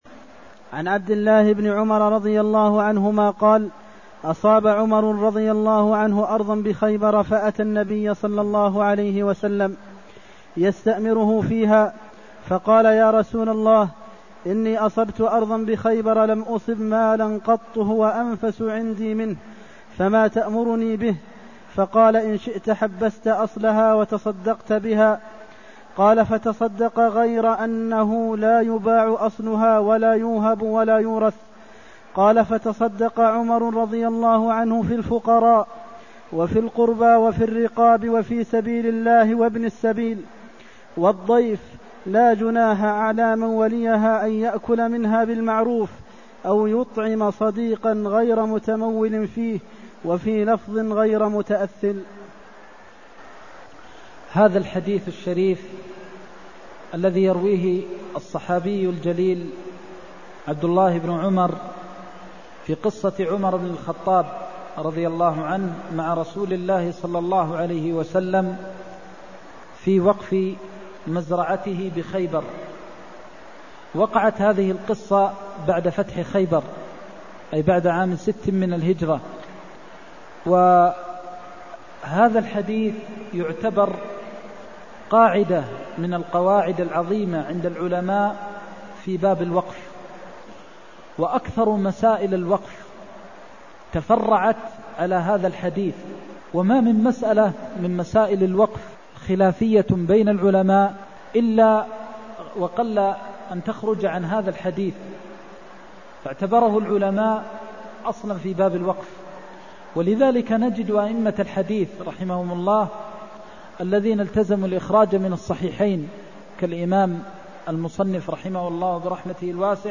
المكان: المسجد النبوي الشيخ: فضيلة الشيخ د. محمد بن محمد المختار فضيلة الشيخ د. محمد بن محمد المختار إن شئت حبست أصلها وتصدقت بها (269)